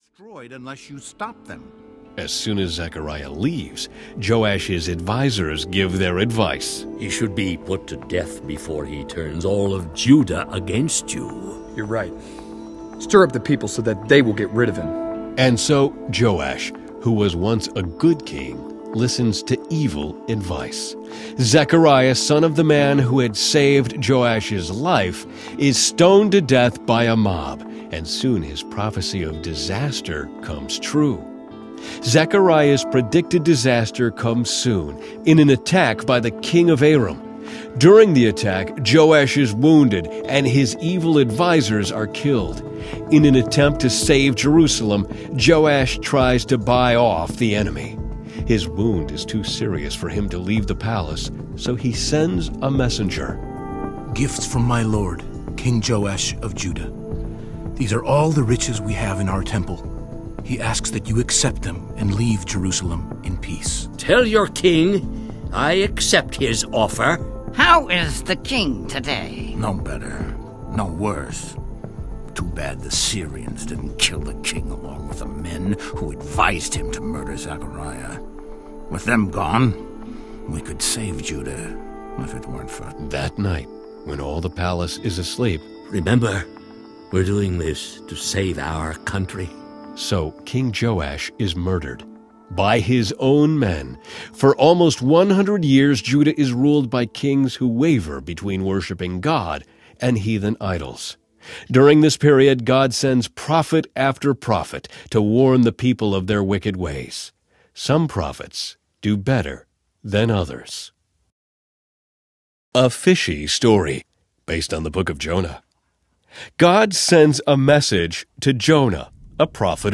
The Action Bible Audiobook
This compelling blend of clear writing plus dramatic voice characterization offers an appeal that crosses all age boundaries.
10.25 Hrs. – Unabridged